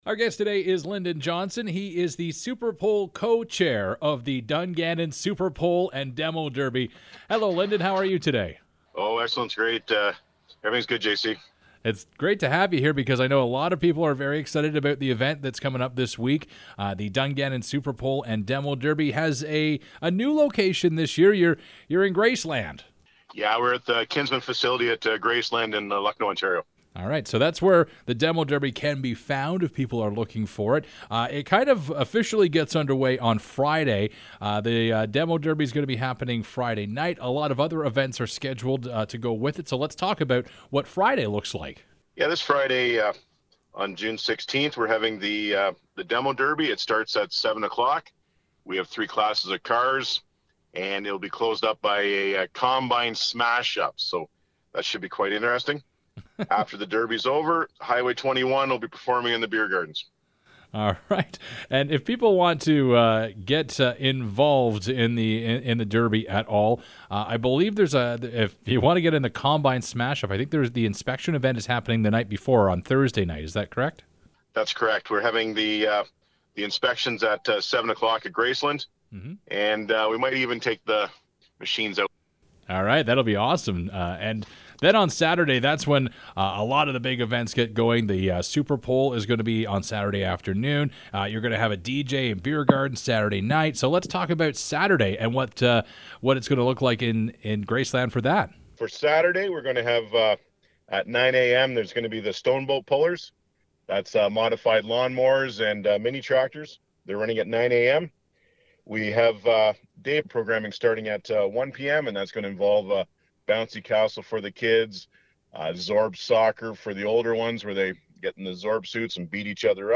Dungannon Super Pull & Demo Derby: Interview